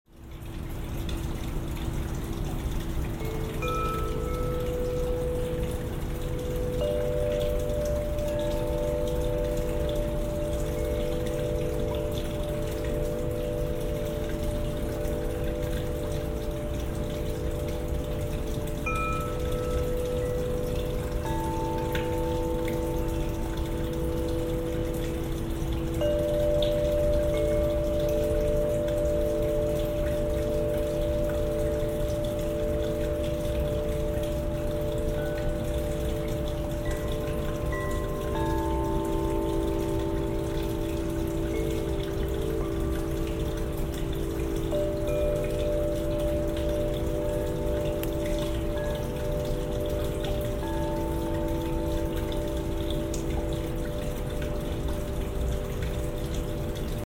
Wind chimes bring a unique, sound effects free download
Wind chimes bring a unique, soothing musical presence to outdoor spaces. They can have a calming effect, bringing peace to your environment.